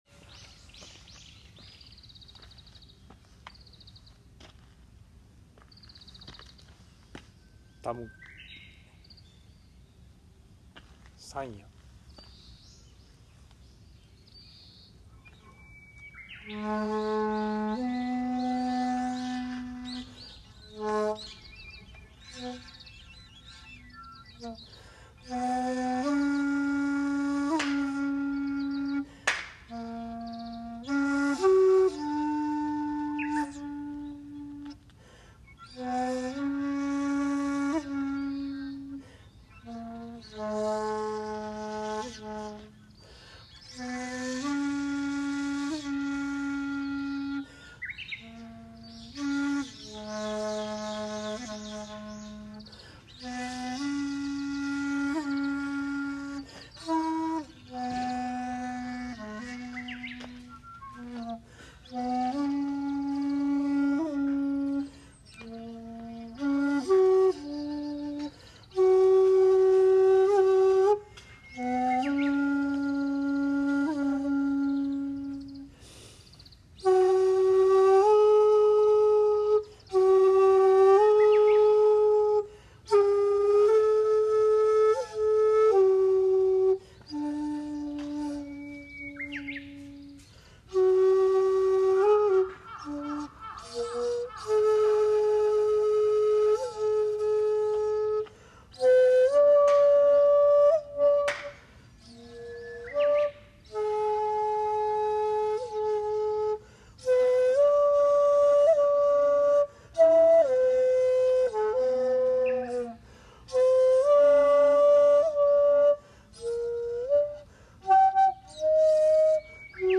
今日最初の札所なので納経して、尺八を吹奏して奉納しました。
（写真② 八栗寺本堂前で尺八吹奏）
（尺八音源：八栗寺にて「三谷」）